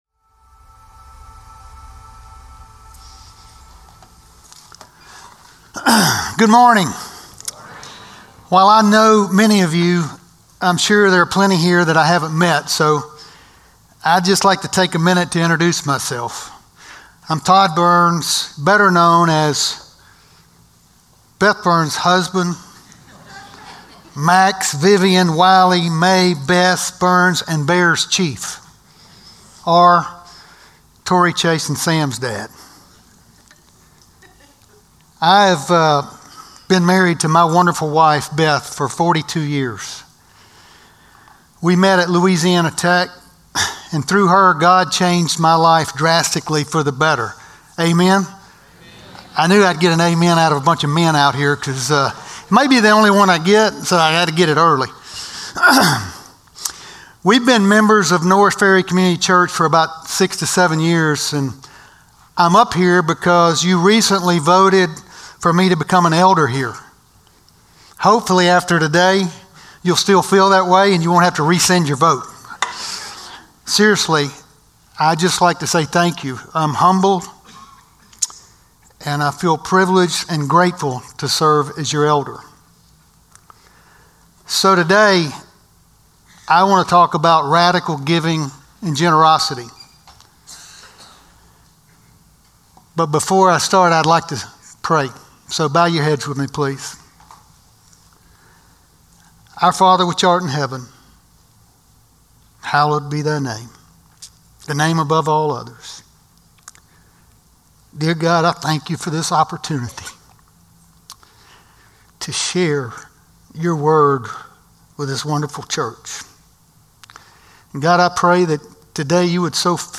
Norris Ferry Sermons June 29, 2025 -- Vision 2025 Week 5-- 2 Corinthians 9:6-15 Jun 29 2025 | 00:25:45 1x 00:00 / 00:25:45 Subscribe Share Spotify RSS Feed Share Link Embed